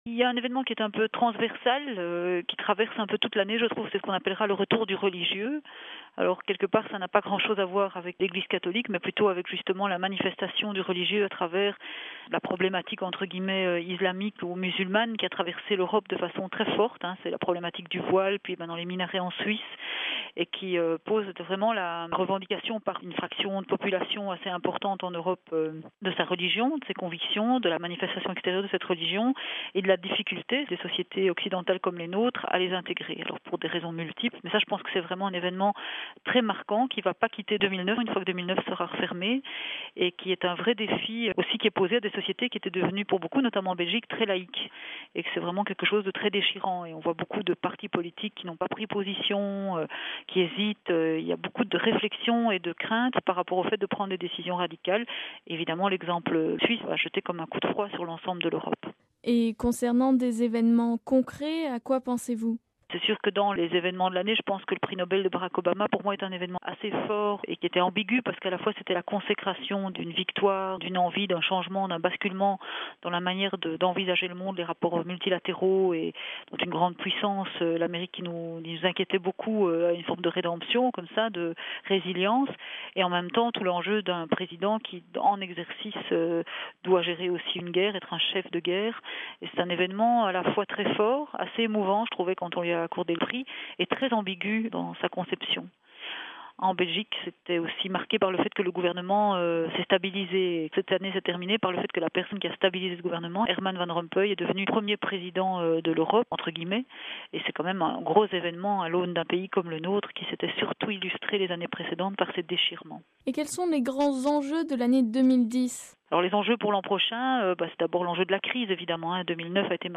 Nous vous proposons d’écouter une série d’entretiens de fin d’année avec quelques responsables de la presse écrite francophone.